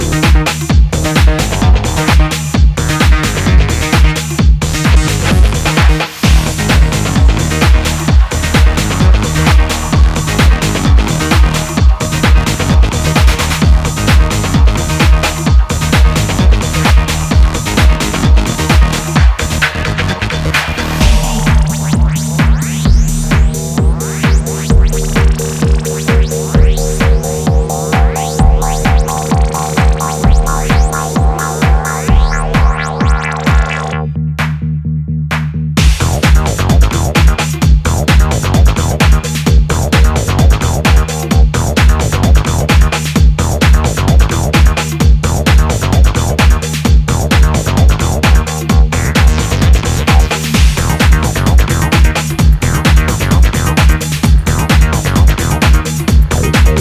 electronic duo